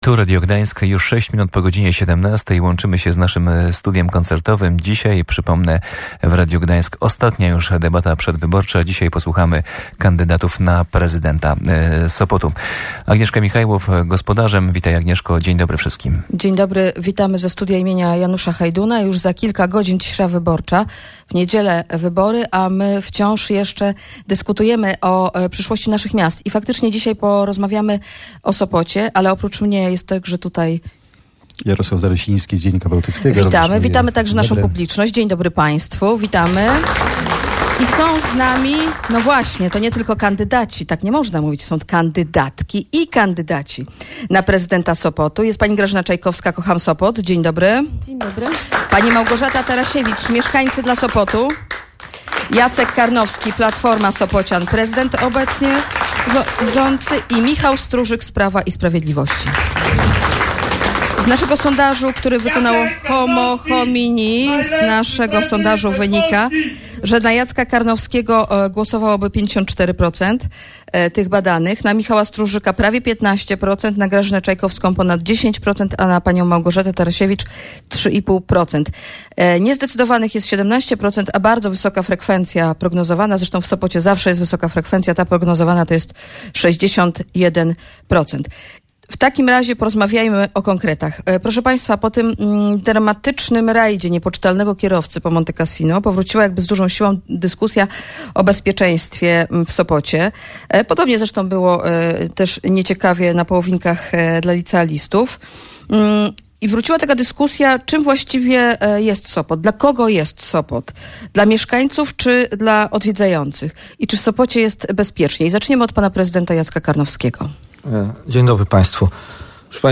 Dyskusja odbyła się w naszym Studiu Koncertowym im. Janusza Hajduna. To już ostatnia przedwyborcza debata, której można było na żywo posłuchać w Radiu Gdańsk i w internecie.